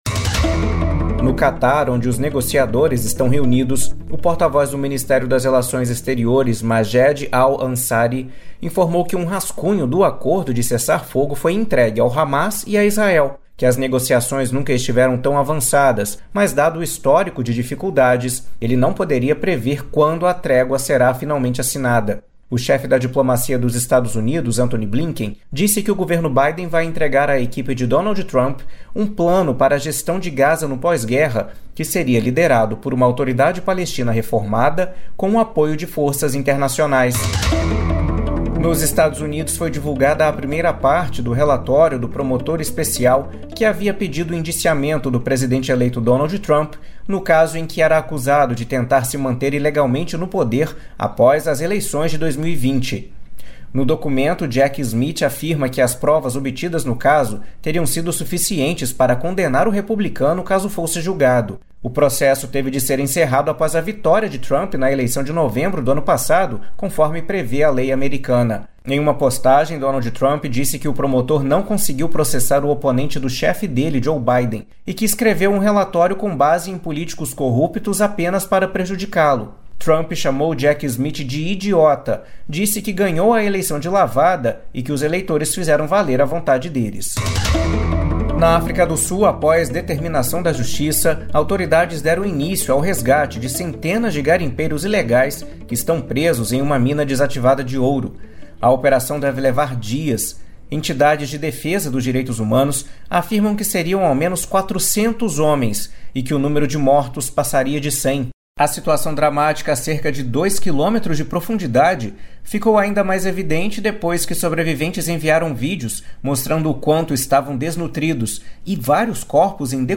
Em entrevista coletiva, a presidente do Palmeiras, Leila Pereira, falou sobre as saídas dos atacantes e criticou Dudu.